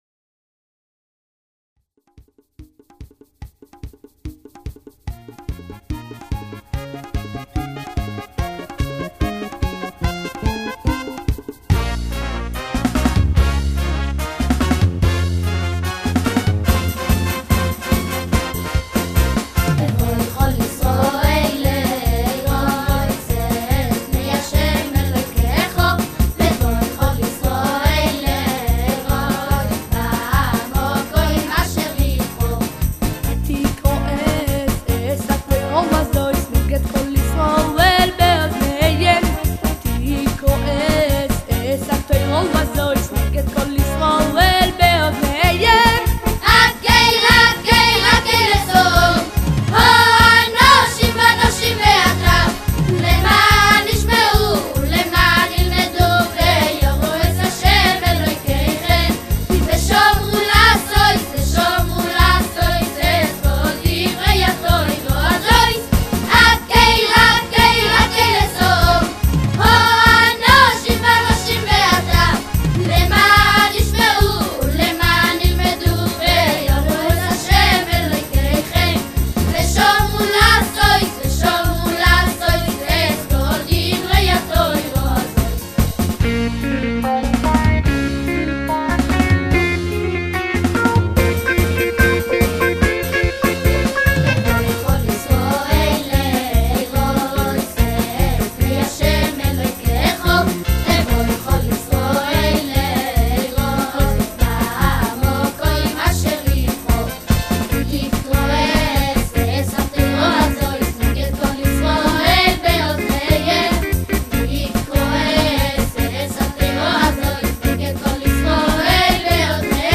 בשנה שעברה הוקמה מקהלת ילדים מתלמידי ה'חדר'
המקהלה מופיעה באירועים השונים המתקיימים במוסקבה לרוב. בשבוע שעבר, במעמד הקהל מרכזי לקהילת השלוחים במוסקבה, הופיעה המקהלה בשיר חדש ומיוחד לשנת "הקהל".